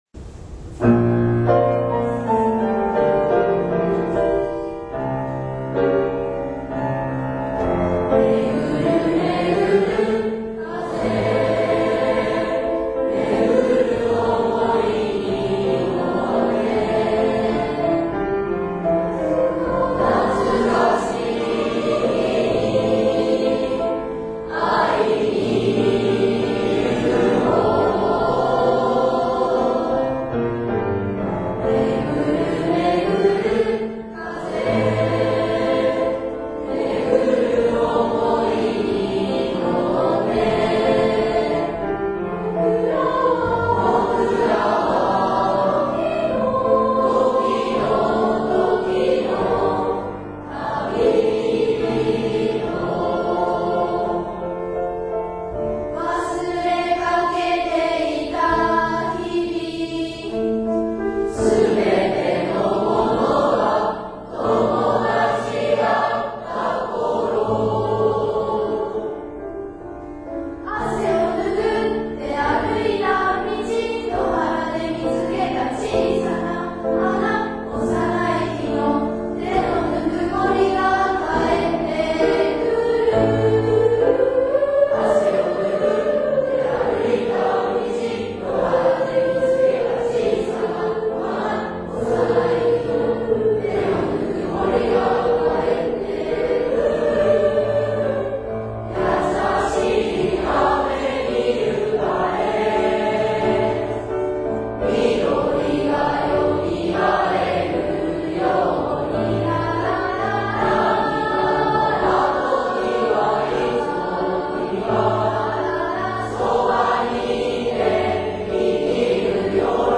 〜「卒業生を送る会」での1・2年生の歌声を春風に乗せて〜 卒業生を送る会「時の旅人」